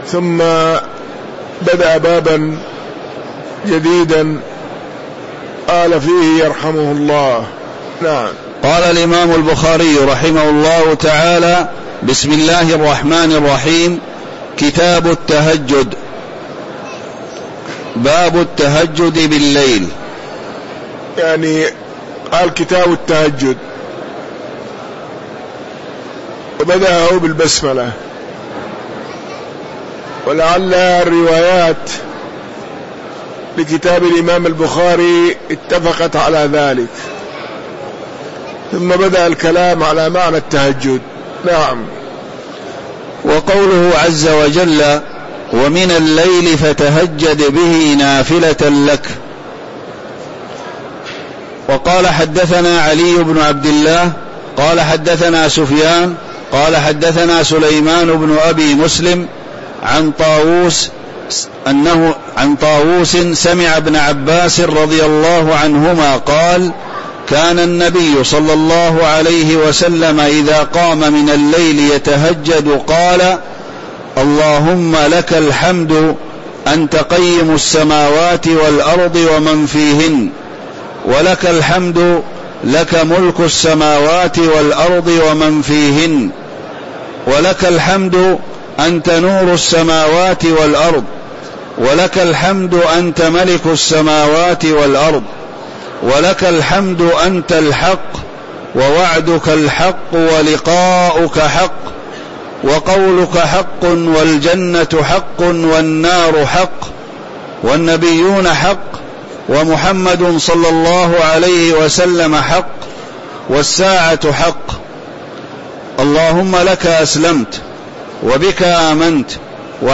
تاريخ النشر ١٧ رمضان ١٤٤٣ هـ المكان: المسجد النبوي الشيخ